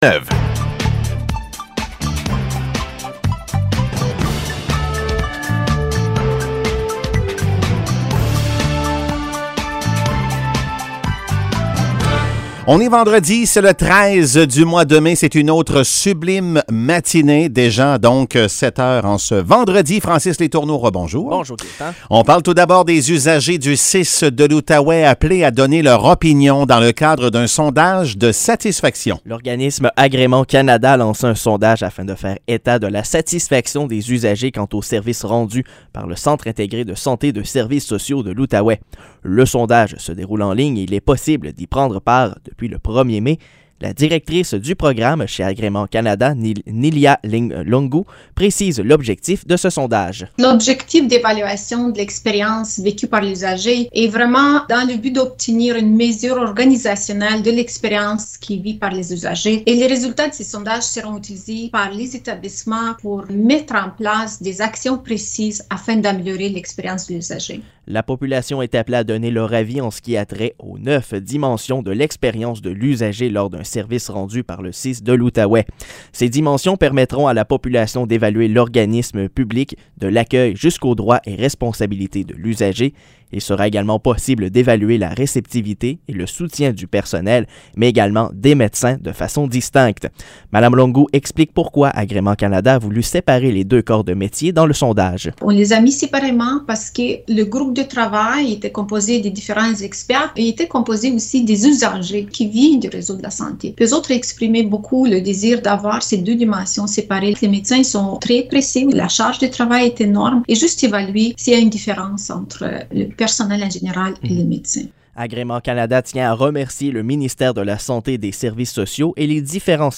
Nouvelles locales - 13 mai 2022 - 7 h